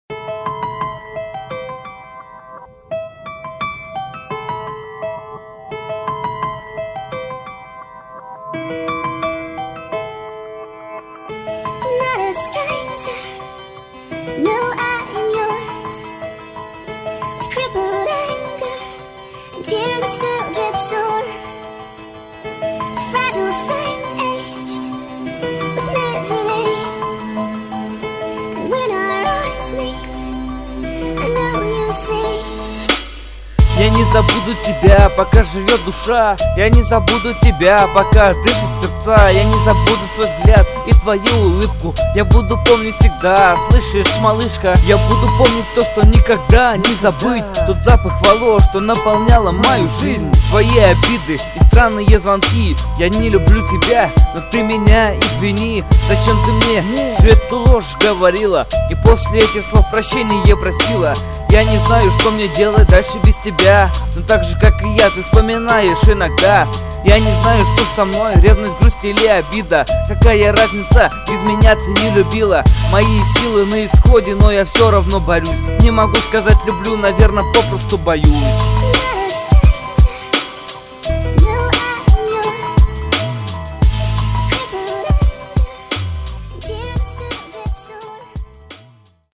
Жанр-рэп